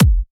kick1_4.ogg